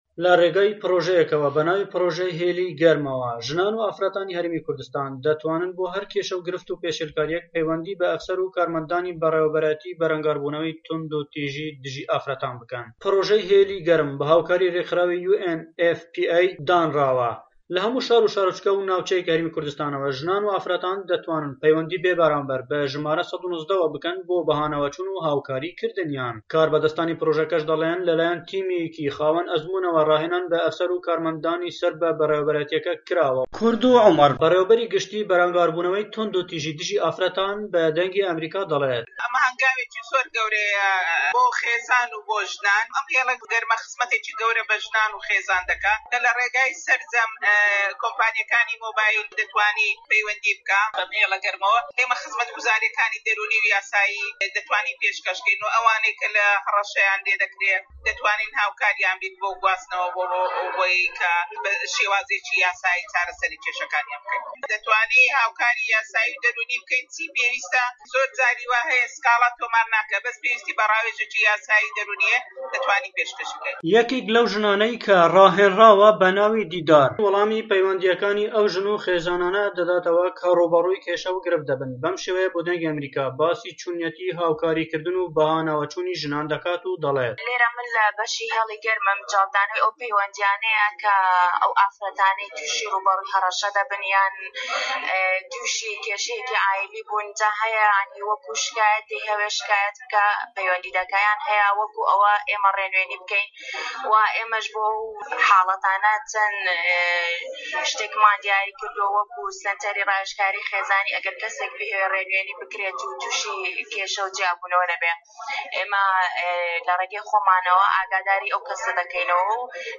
کوردۆ عومەر بەڕیوەبەری گشتی بەرەنگاربوونەوەی توندوتیژی دژی ئافرەتان بە دەنگی ئەمەریکا دەڵێت ئەم هێلە گەرمە خزمەتێکی گەورە بە ژنان و خێزان دەکات ، لە رێگای سەرجەم کۆمپانیاکانی مۆبایلەوە دەتوانرێت پەیوەندی بەو هێلە گەرمەوە بکرێت ، خزمەتگوزاری دەرونی و یاساییان پێشکەش دەکرێت و ئەوانەی کە هەڕەشەیان لێ دەکرێت لە رێگای یاساییەوە کێشەکانیان بۆ چارەسەر دەکرێت.